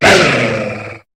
Cri de Lougaroc dans sa forme Diurne dans Pokémon HOME.
Cri_0745_Diurne_HOME.ogg